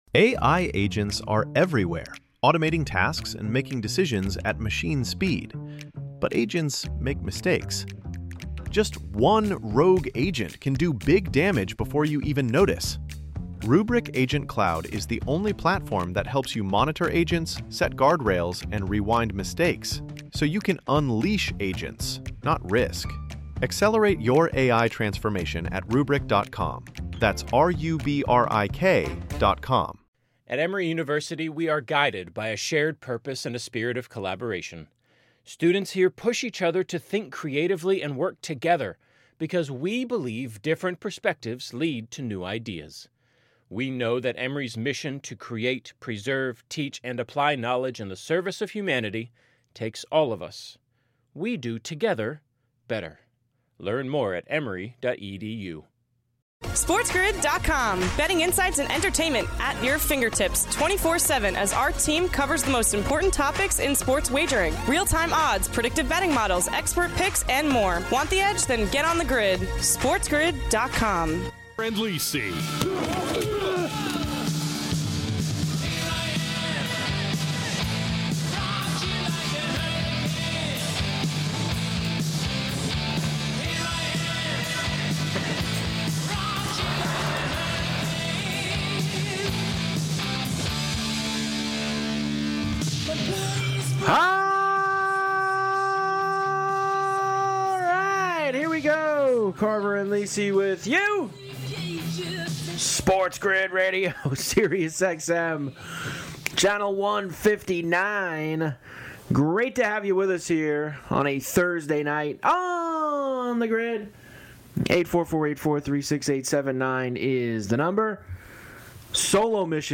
flying solo for tonight's episode